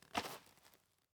Illusion-UE5/Dirt Walk - 0005 - Audio - Dirt Walk 05.ogg at dafcf19ad4b296ecfc69cef996ed3dcee55cd68c